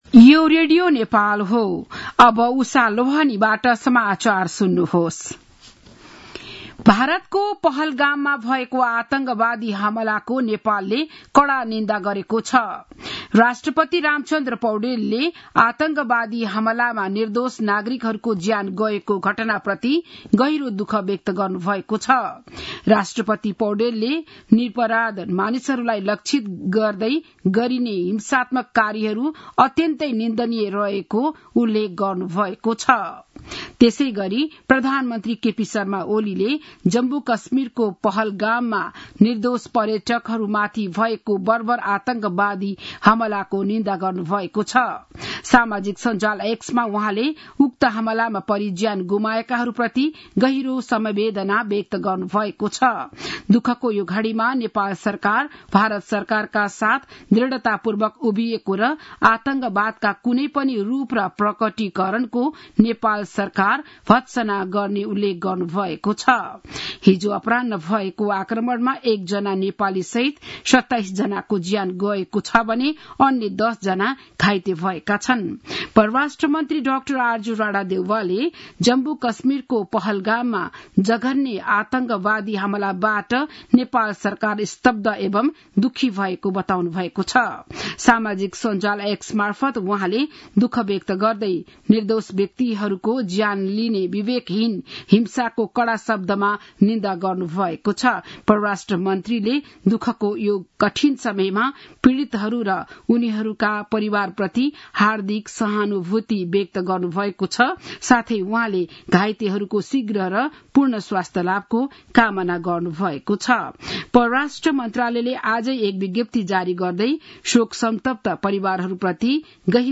बिहान ११ बजेको नेपाली समाचार : १० वैशाख , २०८२
11-am-news-1-11.mp3